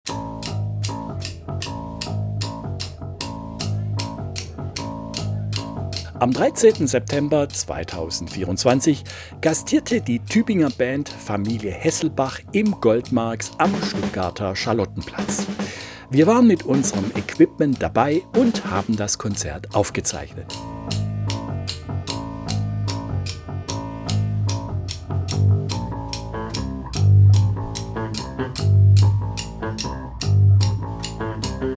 Saxofon.